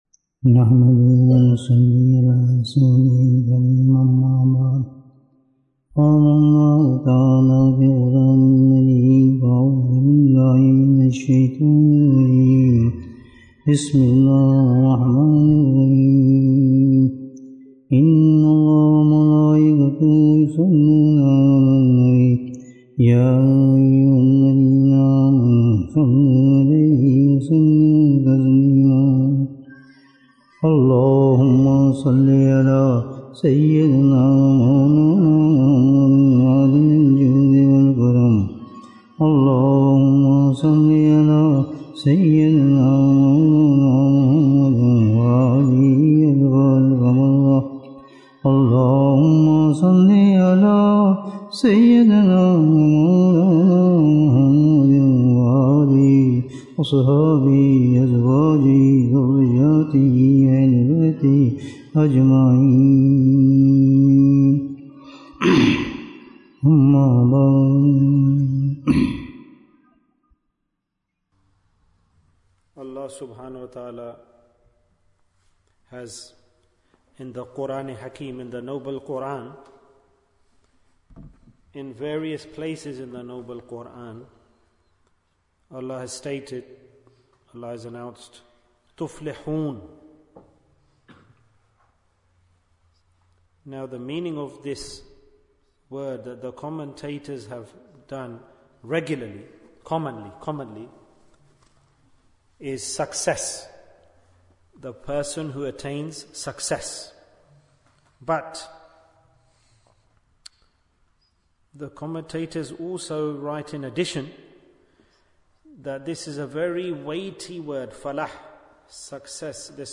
Wazifa for all Problems Bayan, 25 minutes20th June, 2024